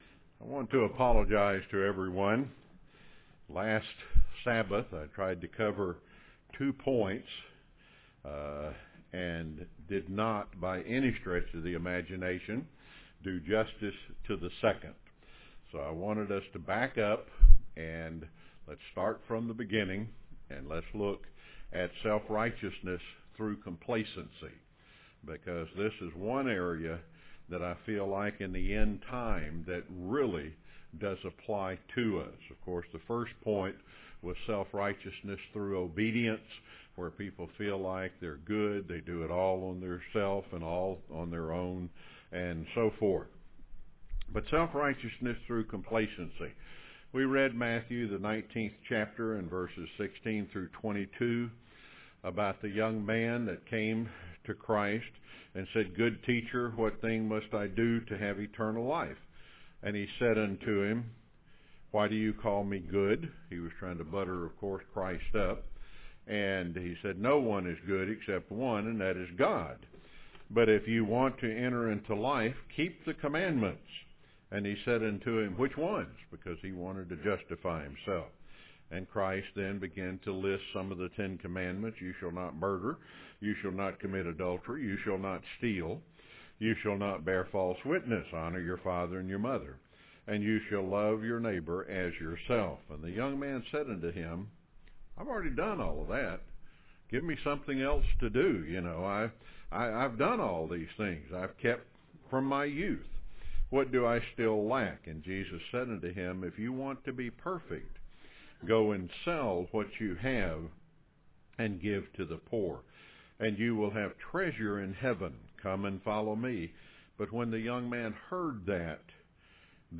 Given in Rome, GA
UCG Sermon Studying the bible?